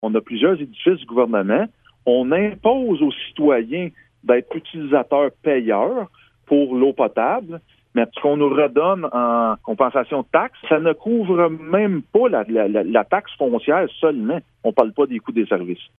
La Ville confirme être en démarche auprès du député de Gatineau, Robert Bussière, afin de faire cheminer ce dossier. Le maire Caron explique que les compensations reçues de Québec pour les édifices gouvernementaux situés à Gracefield sont nettement insuffisantes :